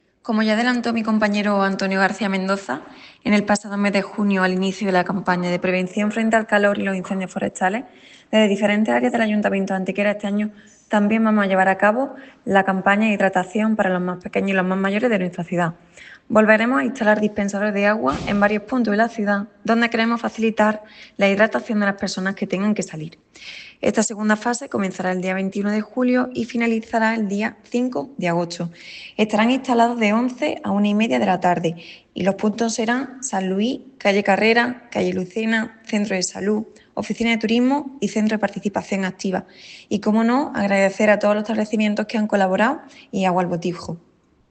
La concejal delegada de Familia y Mayores, María Sierras, ha recordado que “esta campaña da continuidad a la ya iniciada en junio por parte del Área de Seguridad dentro del plan de prevención frente al calor y los incendios forestales.
Cortes de voz